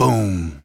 playerVoiceLong2.wav